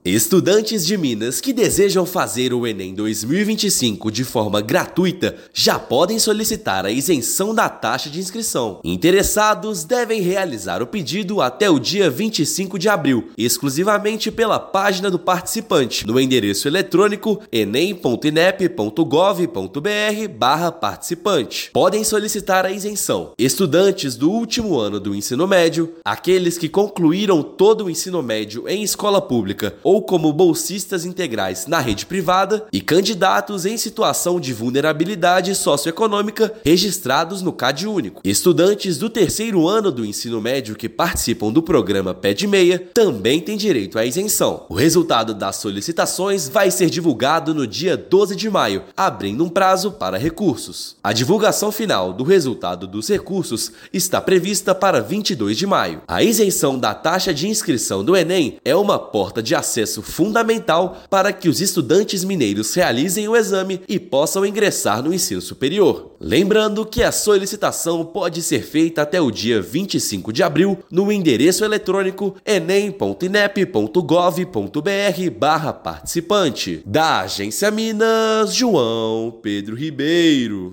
Candidatos elegíveis têm até 25/4 para solicitar a gratuidade pela Página do Participante; CPF é documento obrigatório para participar do exame. Ouça matéria de rádio.